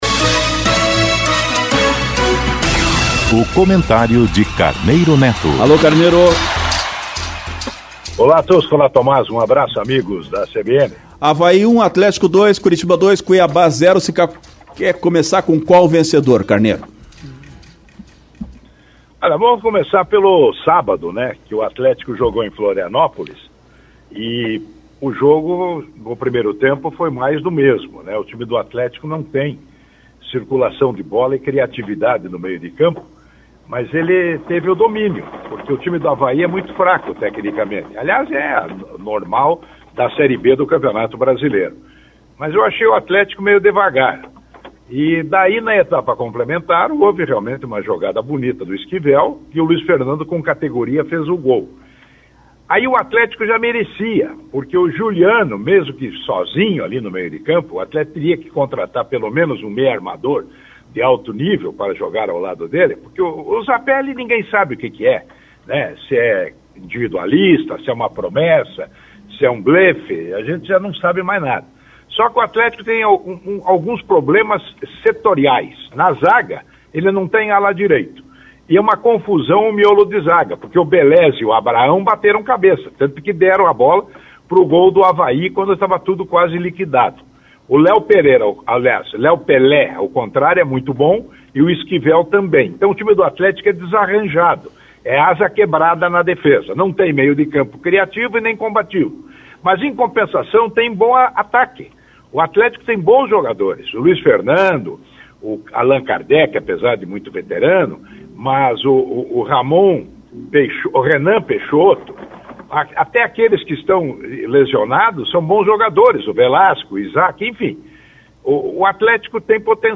Confira o comentário completo